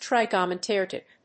音節trig・o・no・met・ric 発音記号・読み方
/trìgənəmétrɪk(米国英語)/
trigonometric.mp3